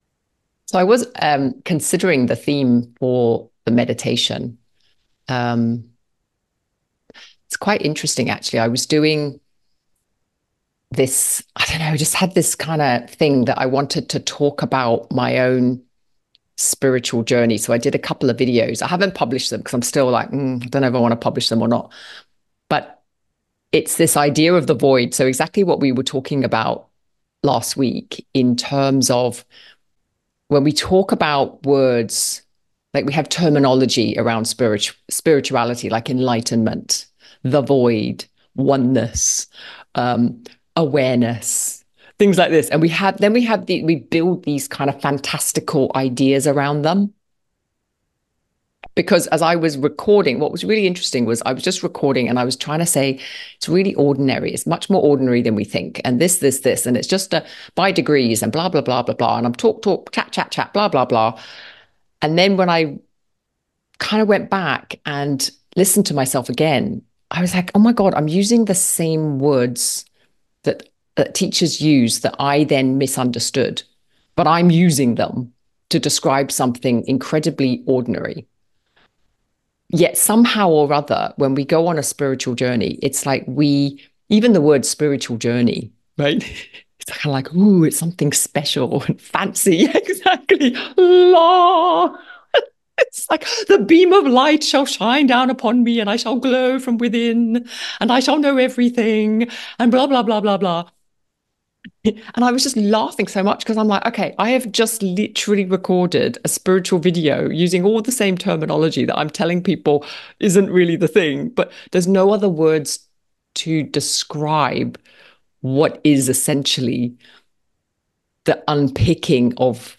Excerpt and meditation from our weekly community gathering Meditation timestamp: 8:40 mins Looking for a Reiki community?